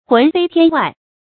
魂飛天外 注音： ㄏㄨㄣˊ ㄈㄟ ㄊㄧㄢ ㄨㄞˋ 讀音讀法： 意思解釋： 靈魂脫離軀體飛到天空之外。